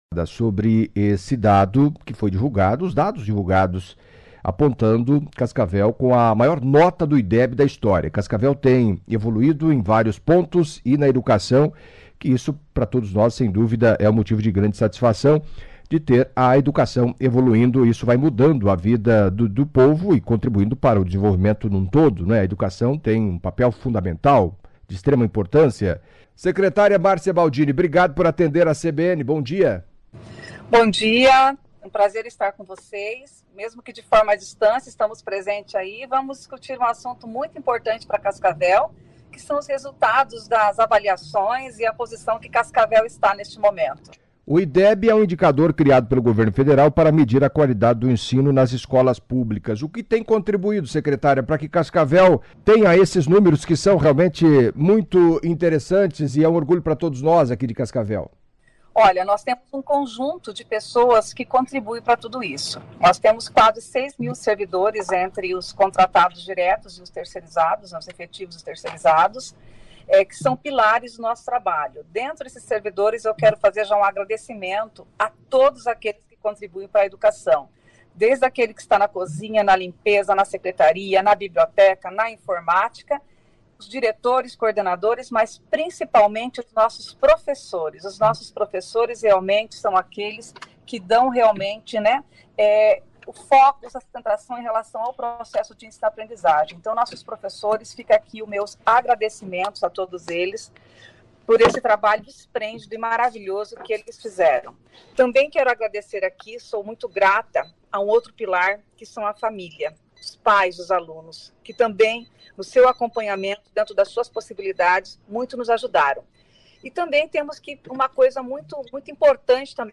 Em entrevista à CBN nesta segunda-feira (19) Márcia Baldini, secretária de Educação de Cascavel, no Oeste do Paraná, destacou as ações desenvolvidas pela pasta que colocam o município, de 350 mil habitantes, como referência no Brasil.